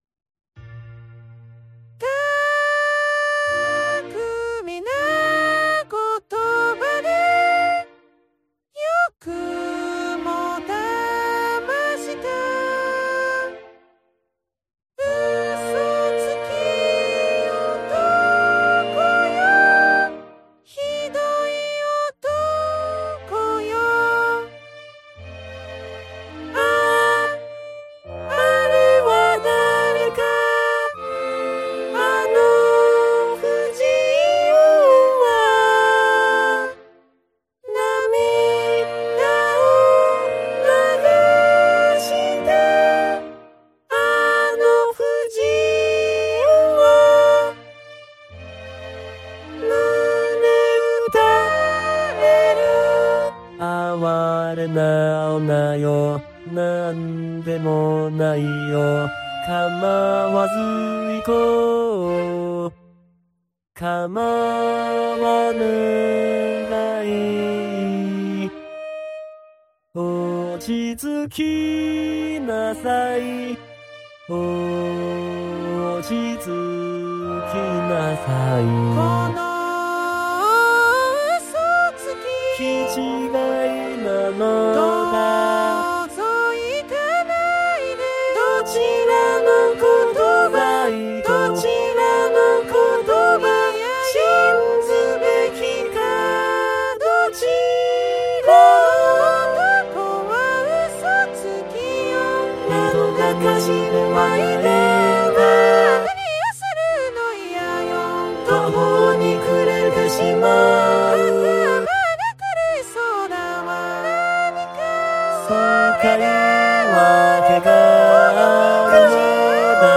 男声はGackpoidかKaitoを、女声は初音ミクNTを使いました。
管弦楽はGarritan Personal Orchestra5(VST)を使ってMP3形式で保存したものです。